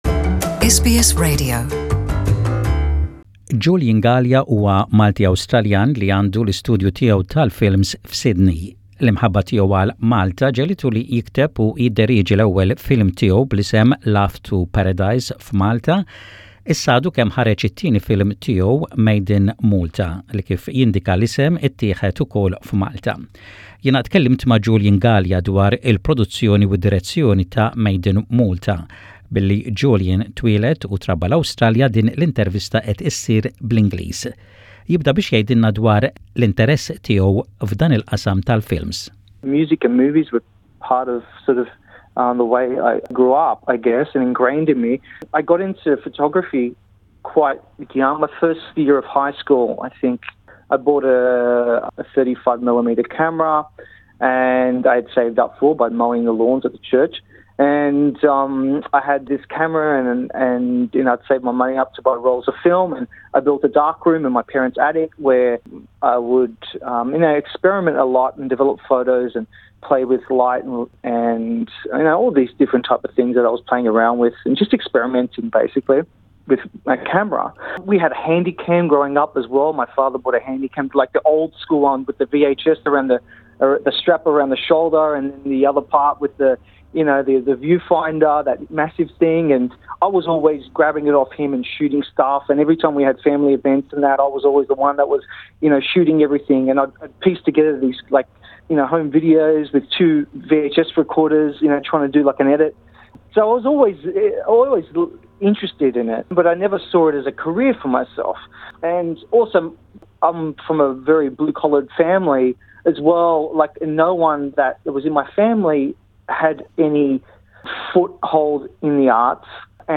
(Interview in English with Maltese introduction).